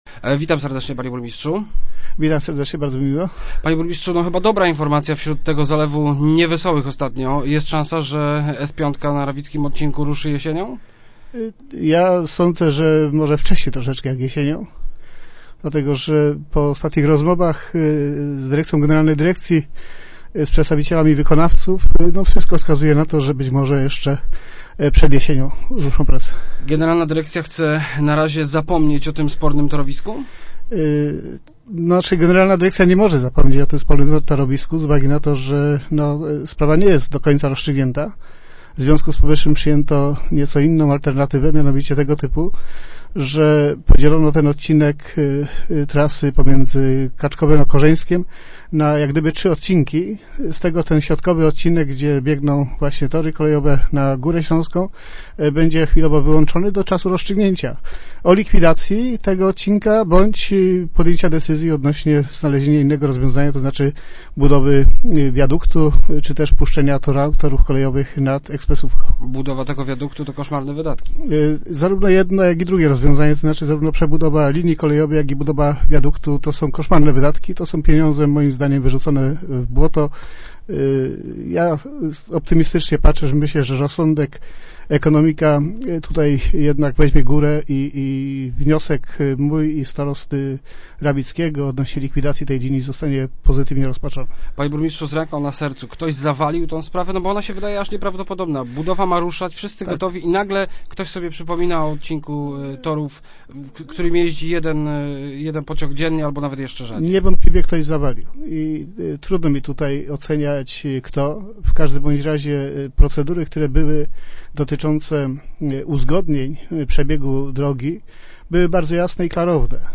- Prace przy budowie rawickiego odcinka ekspresowej piątki mają ruszyć pod koniec lata – zapowiedział dziś w Rozmowach Elki burmistrz Bojanowa, Józef Zuter. Oczekiwana od lat inwestycja będzie jednak dla mieszkańców Bojanowa wymagała dużej cierpliwości. Cały tranzyt na teren budowy będzie wiódł właśnie przez miasteczko.